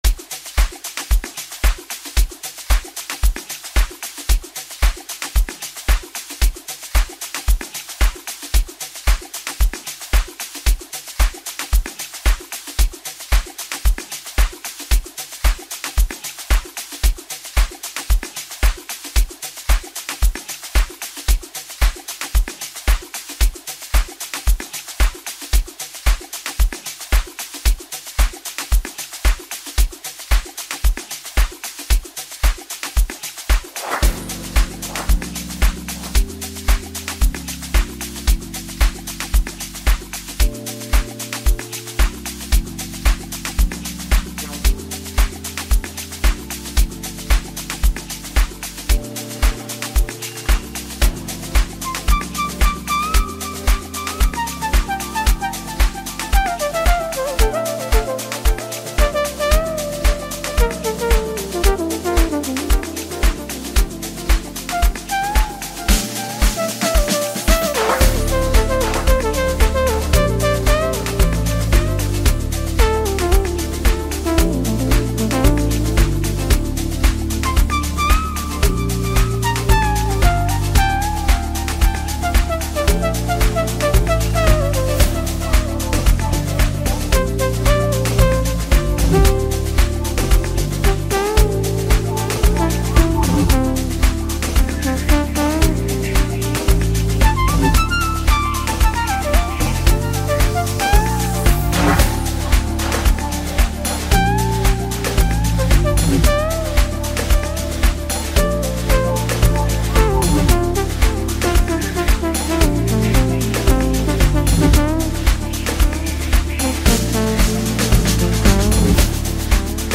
enchanting tune